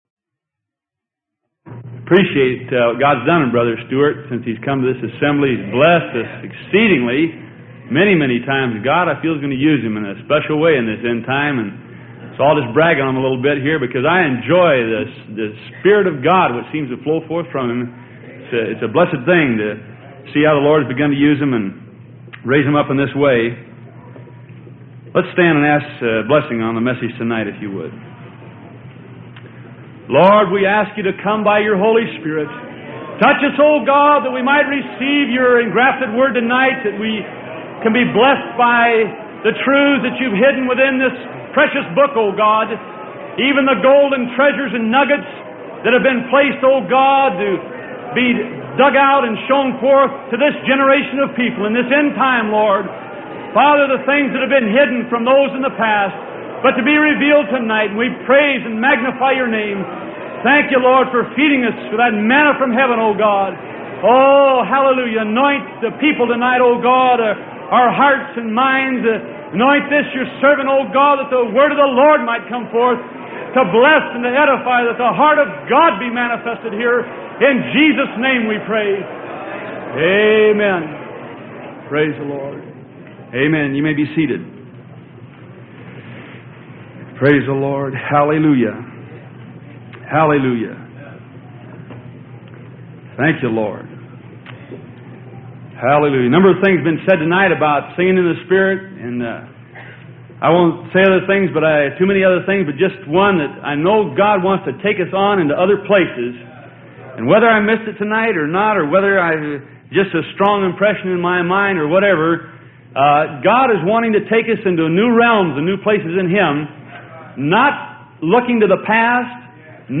Sermon: The Apple of My Eye - The Beloved of the Lord - Freely Given Online Library